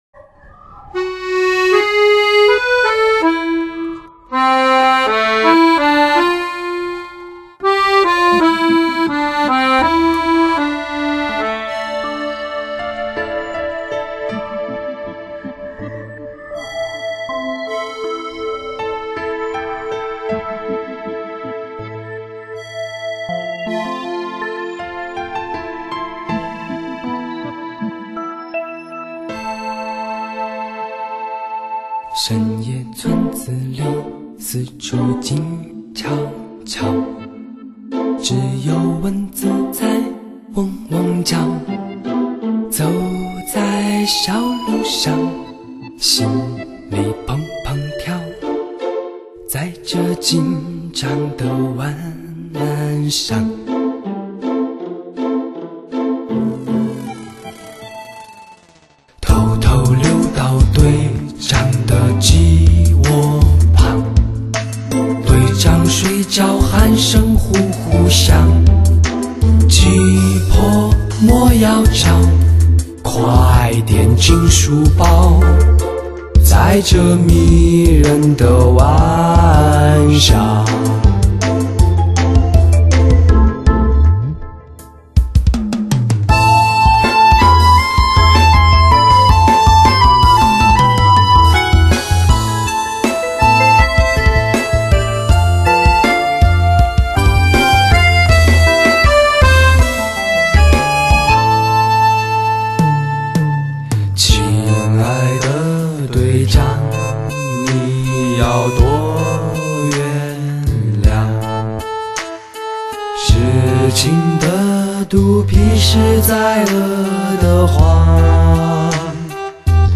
同时音乐本身又具有鲜明的地方风格的歌曲
一段华丽的手风琴伴奏引出的竟是前苏联歌曲